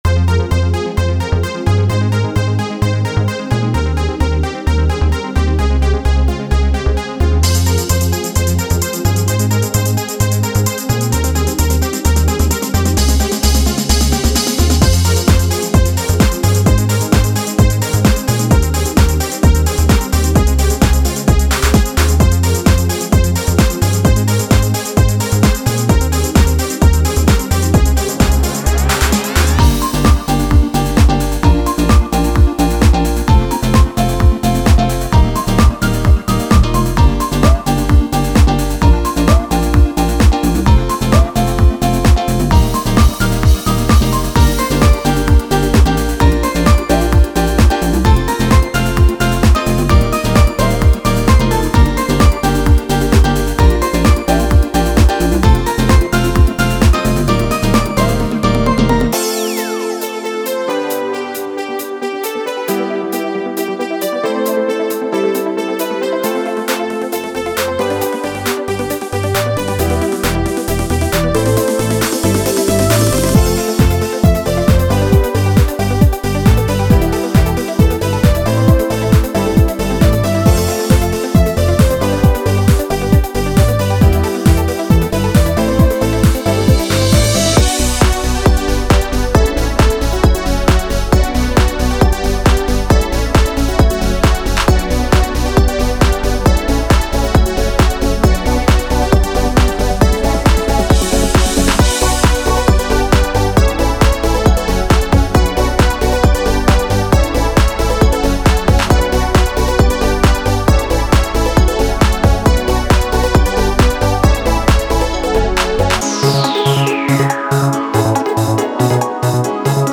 Genre:House
デモサウンドはコチラ↓
Tempo/Bpm: 130-140